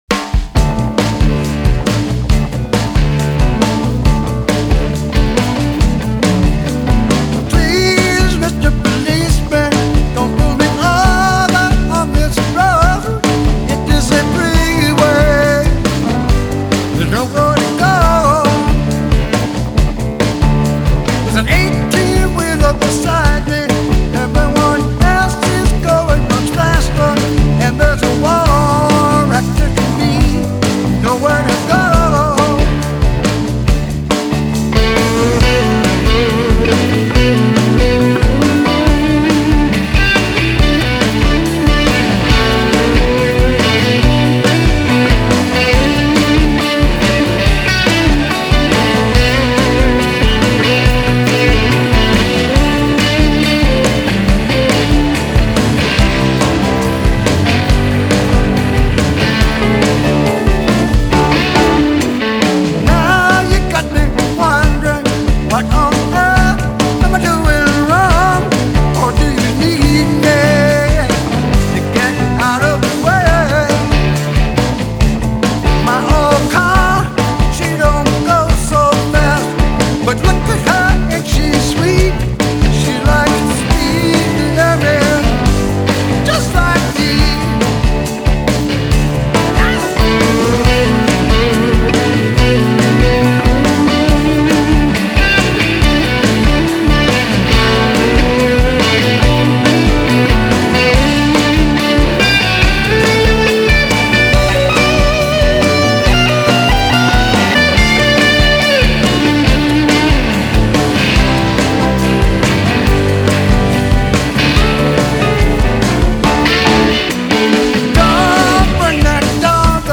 Genre: Blues, Blues Rock